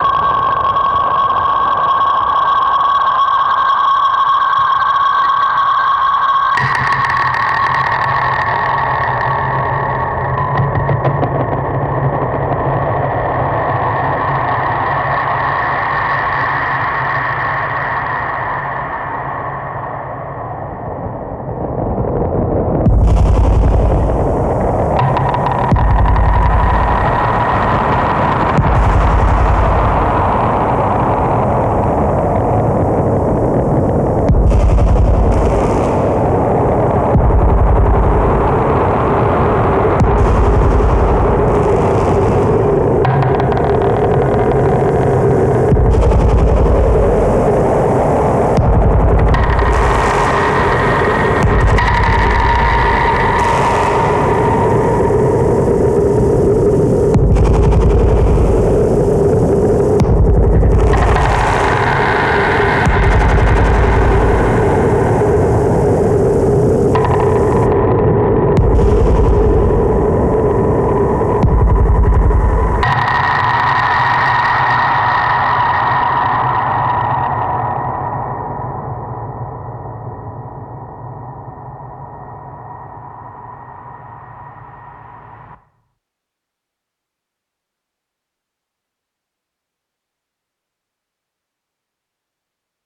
Paper As part of the presentation there was a short audio piece inspired by tape noise and our circular making practice.